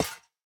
Minecraft Version Minecraft Version snapshot Latest Release | Latest Snapshot snapshot / assets / minecraft / sounds / block / spawner / step2.ogg Compare With Compare With Latest Release | Latest Snapshot
step2.ogg